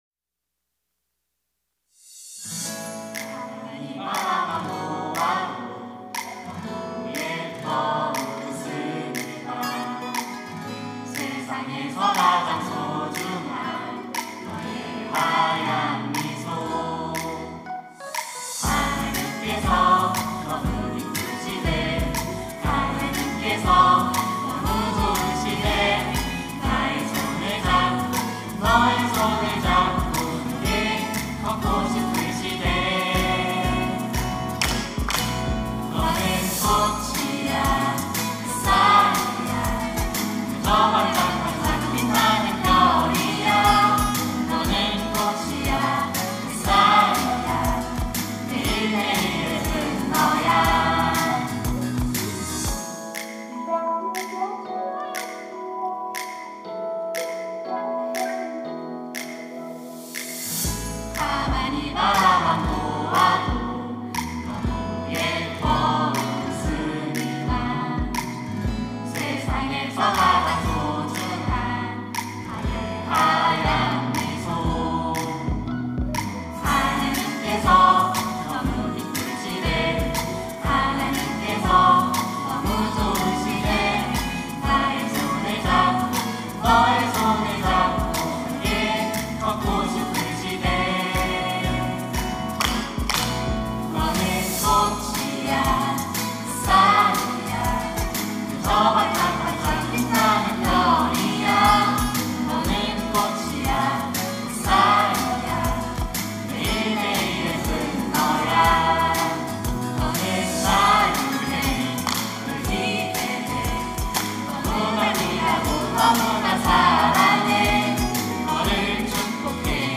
특송과 특주 - 너는 꽃이야